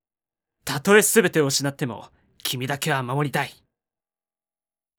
パワフル男性
ボイス